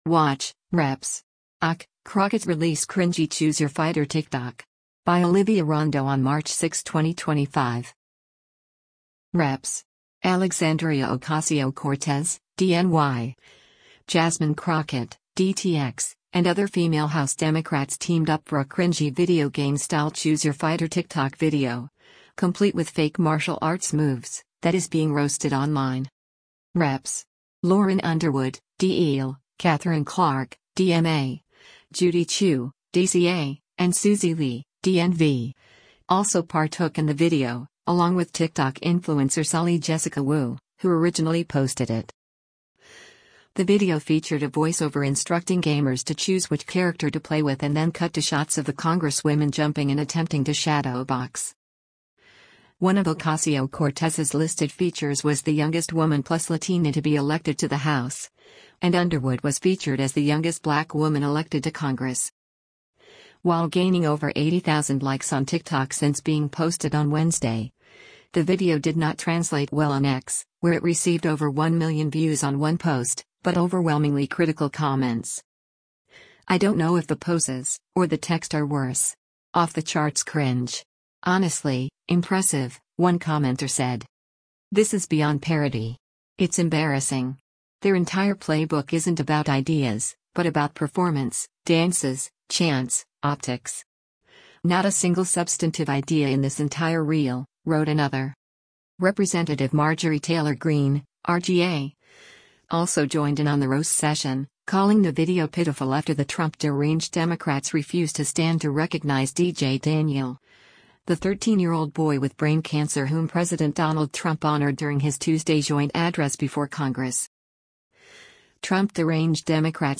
The video featured a voice-over instructing gamers to “choose” which character to play with and then cut to shots of the congresswomen jumping and attempting to shadowbox.